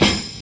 Snare (Answer).wav